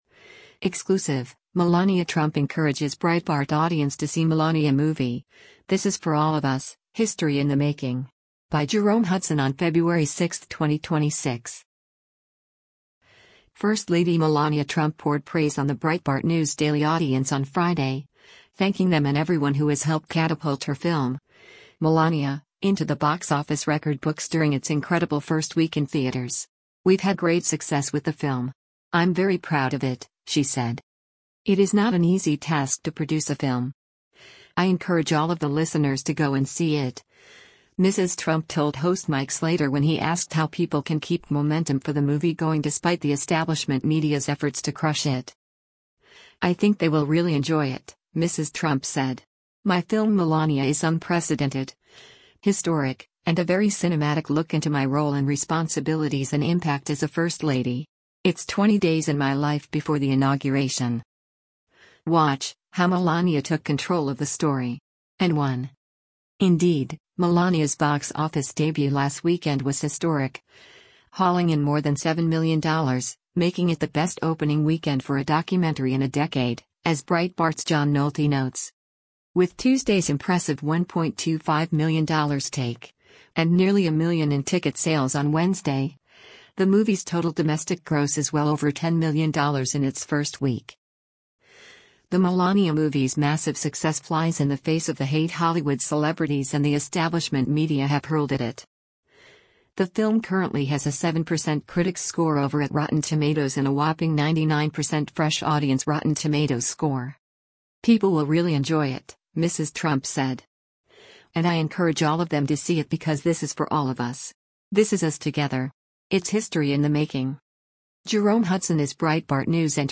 First Lady Melania Trump poured praise on the Breitbart News Daily audience on Friday, thanking them and everyone who has helped catapult her film, Melaniainto the Box Office record books during its incredible first week in theaters. “We’ve had great success with the film. I’m very proud of it,” she said.